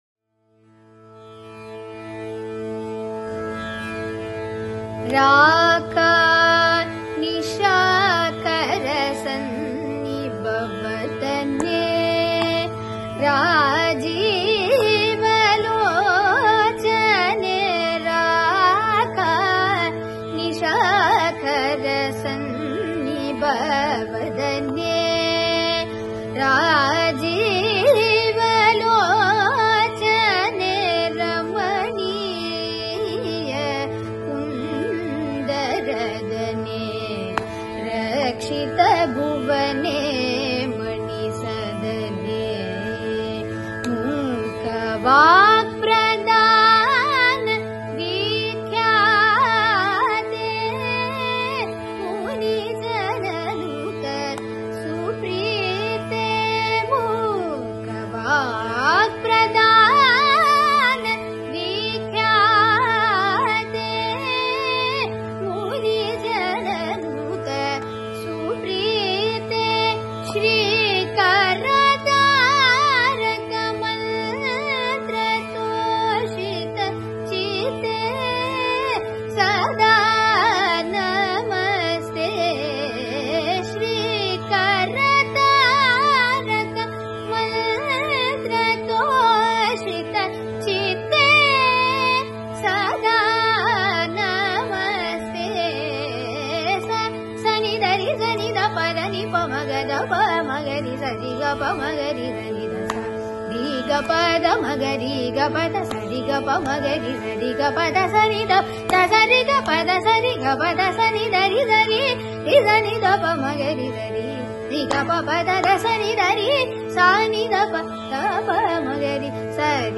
bilahari
Adi